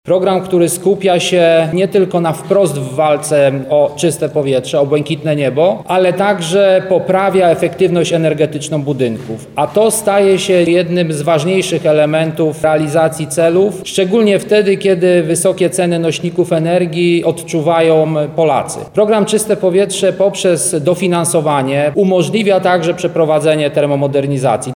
Paweł Mirowski– mówi Paweł Mirowski, wiceprezes NFOŚiGW.